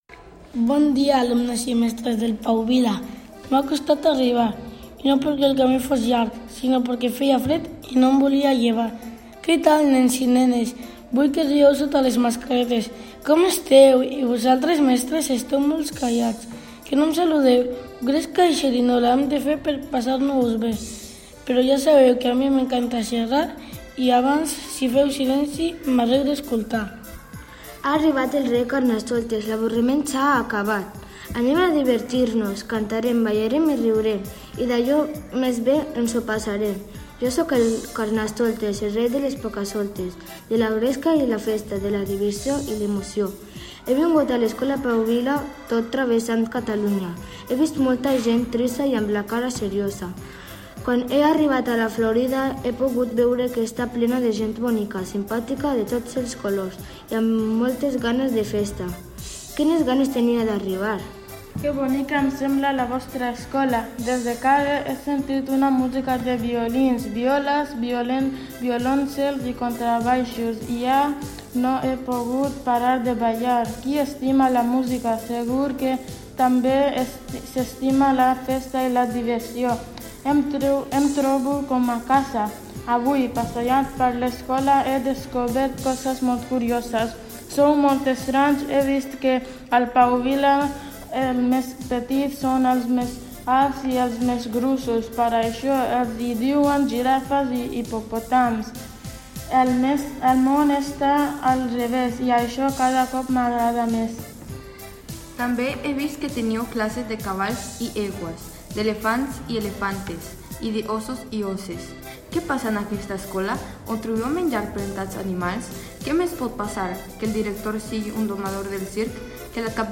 Escolteu el pregó d’inici del Carnaval de Sa Majestat La Reina del Carnestoltes